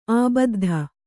♪ ābaddha